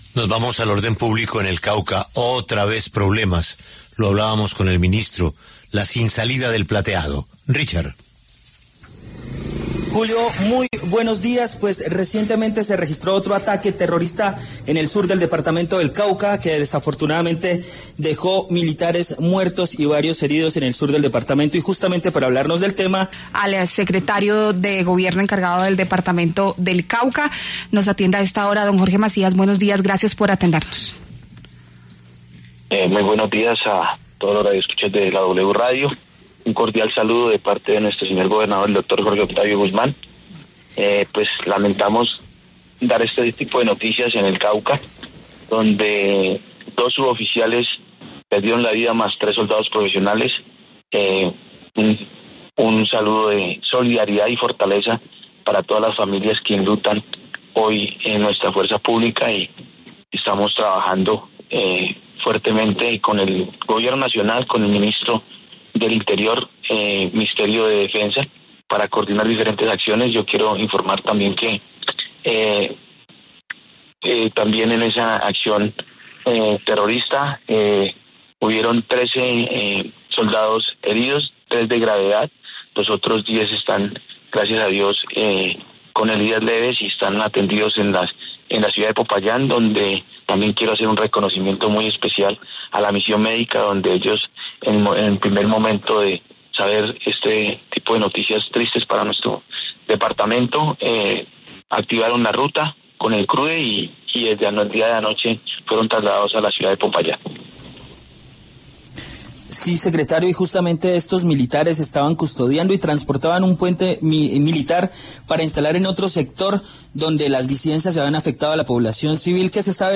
En los micrófonos de La W, el secretario de Gobierno (e) del Cauca rechazó el ataque terrorista que cobró la vida de cinco militares.
El secretario de Gobierno encargado del Cauca, Jorge Macías, en entrevista con La W, rechazó el ataque terrorista contra el Ejército Nacional en el que fueron asesinados cinco militares.